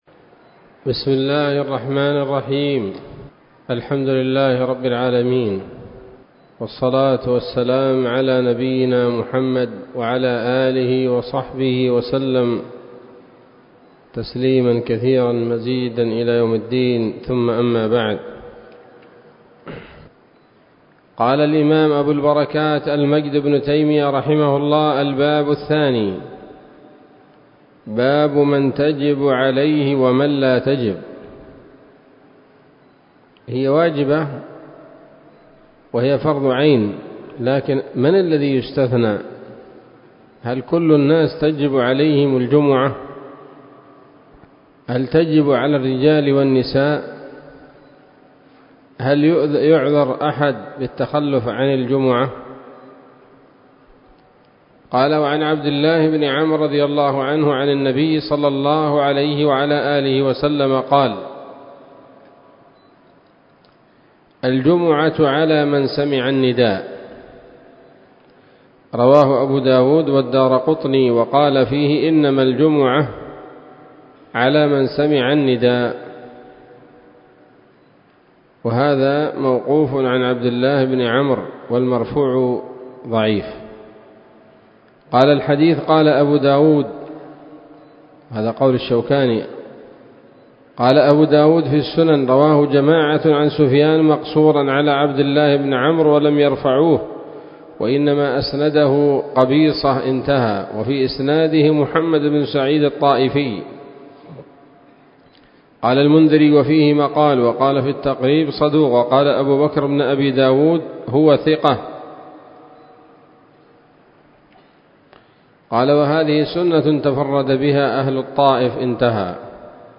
الدرس الثالث من ‌‌‌‌أَبْوَاب الجمعة من نيل الأوطار